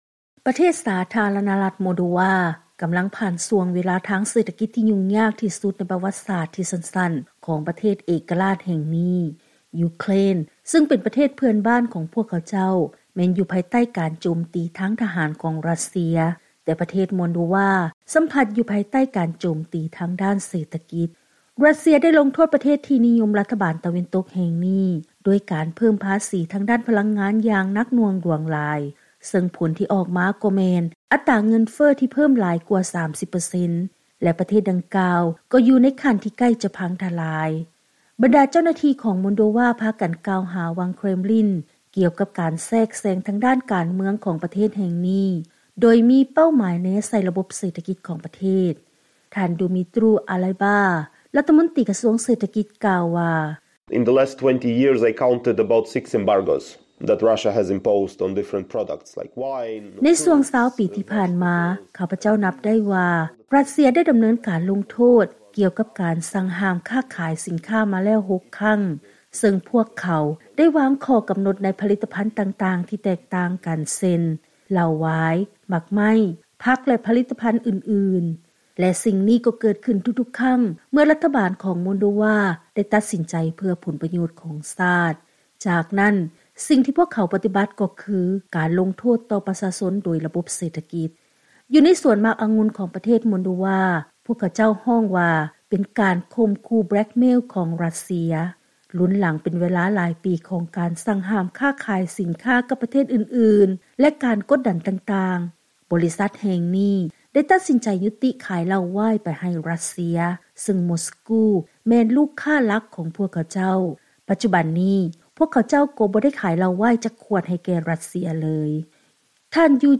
by ສຽງອາເມຣິກາ ວີໂອເອລາວ